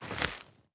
added stepping sounds
Ice_Mono_02.wav